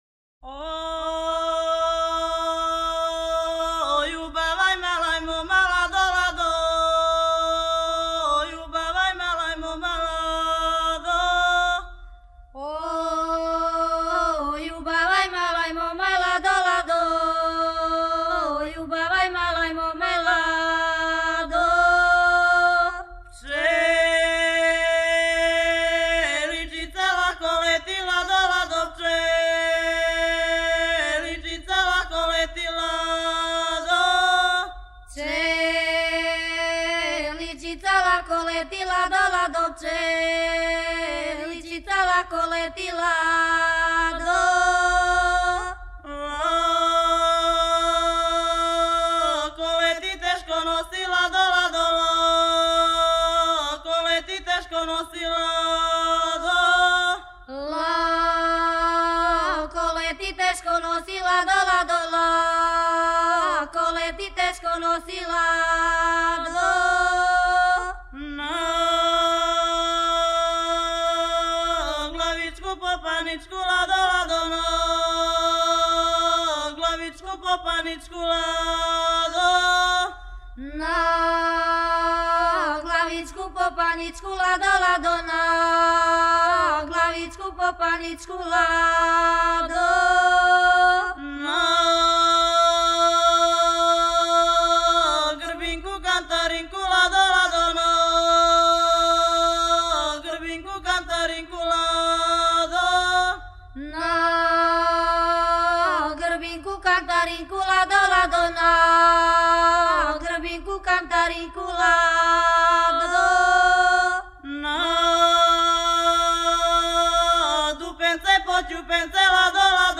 Група девојака из села Брза, Лесковац (3 MB, mp3)
(Овај пример карактерише на завршетку "маткање", тј. рецитатив једне од пјевачица.
Порекло песме: Лесковац
Начин певања: Антифоно